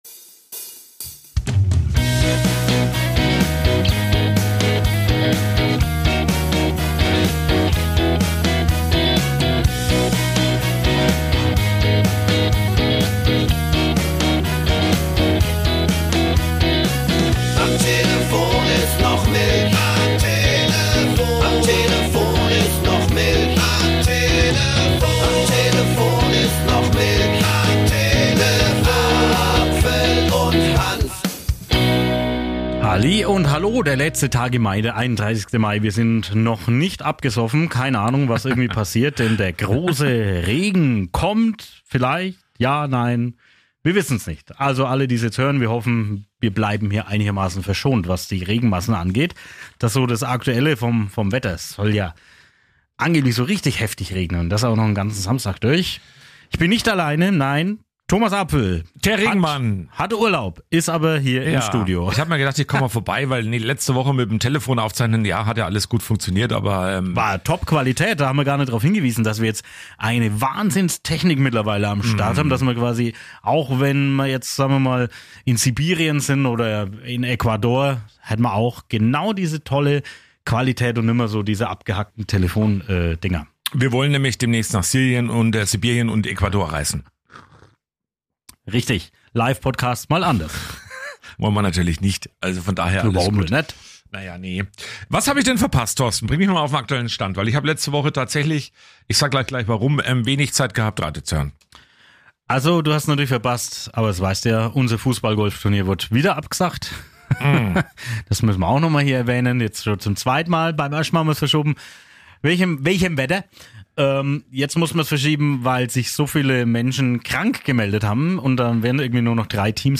Dazu gibt es viele Berichte und Interviews
mit seinem Küpser Dialekt